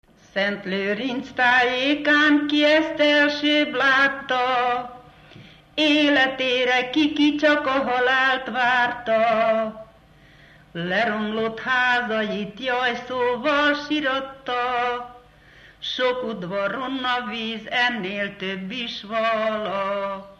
Alföld - Csongrád vm. - Tápé
ének
Stílus: 7. Régies kisambitusú dallamok
Kadencia: 3 (2) 3 1